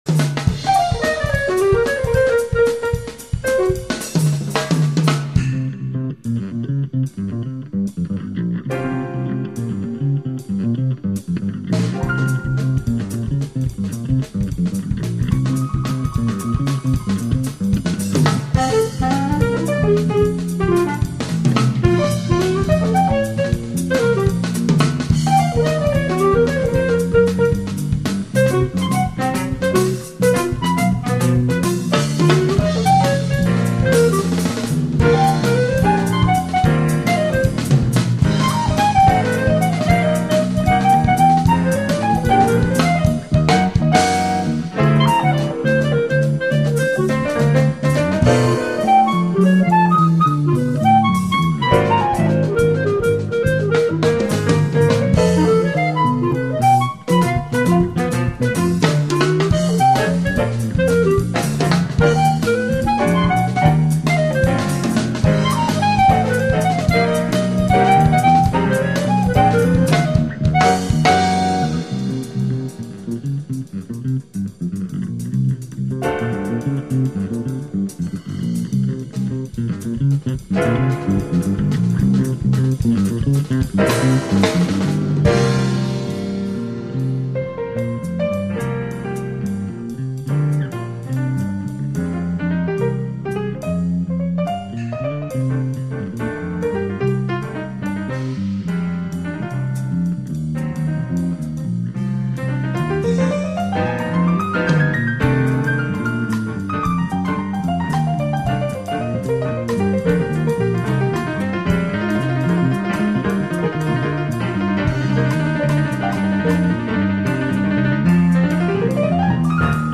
Instrumental Jazz
Música popular: instrumental y jazz